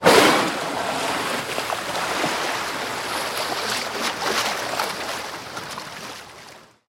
Звуки касаток
Звук: дыхало рвёт воздух (всплеск воды)